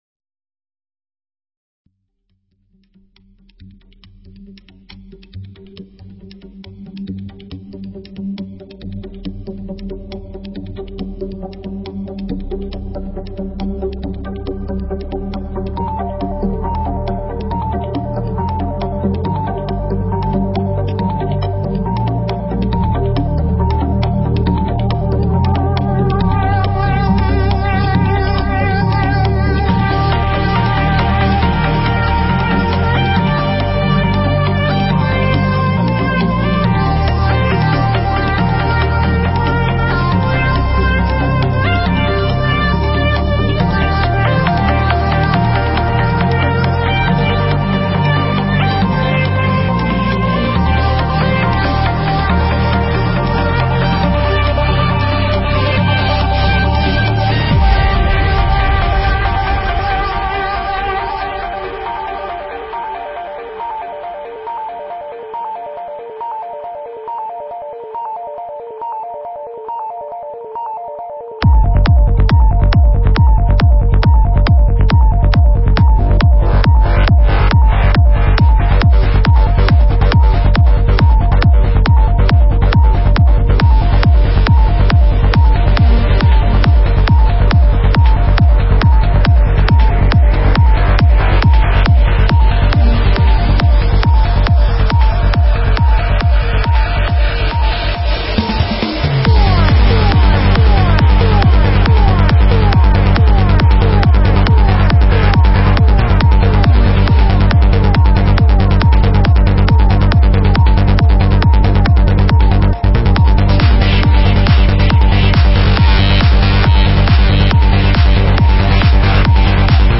Стиль: Trance / Tech Trance